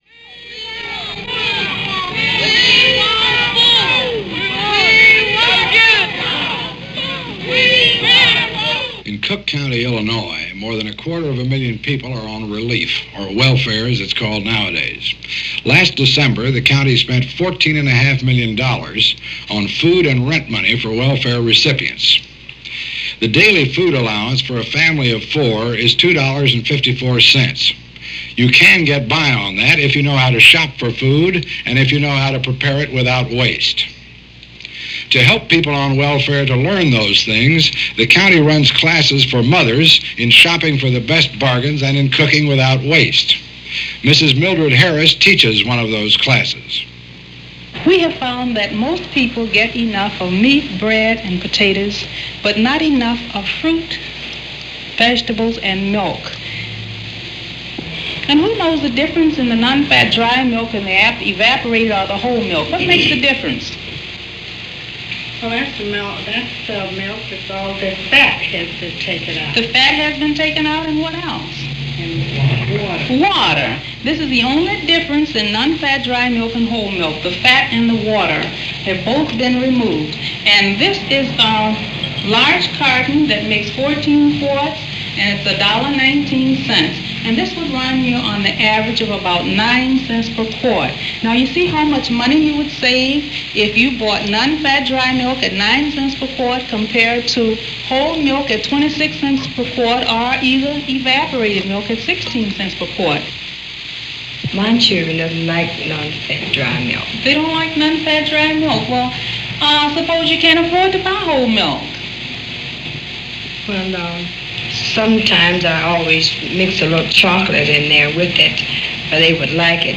Report on Poverty – Hughes Rudd – CBS News – February 18, 1964
Although the poverty of 2023 is somewhat different than the poverty of 1964 (the year of this mini-documentary), the pain, discomfort, anxiety and humiliation are the same.
But then as now, we have the well-intentioned unqualified, like the Home Economist in this piece explaining the difference between real milk and powdered milk and the different ways to fool people into drinking it – someone who probably wouldn’t touch powdered milk with a bargepole, trying to convince the audience of desperate that it’s good for them.
Take a trip back to February 1964 for a report from Hughes Rudd of CBS News on Poverty.